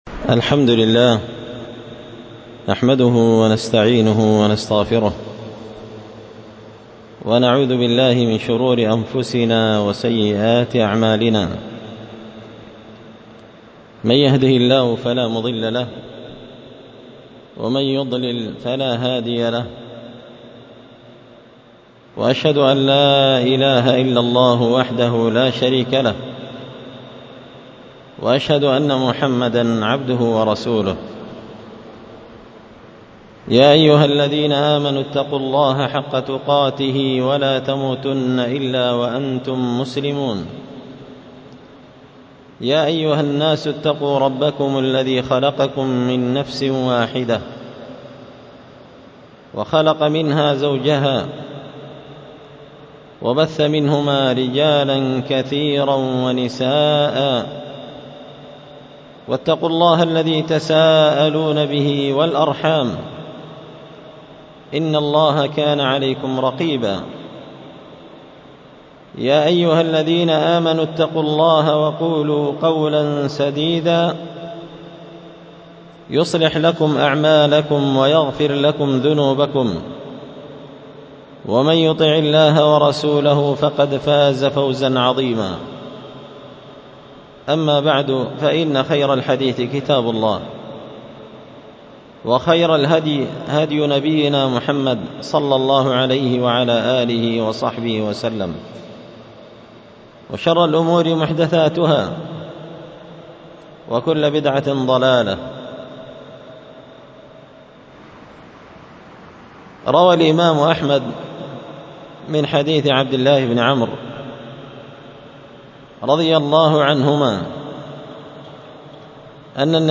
محاضرة بعنوان: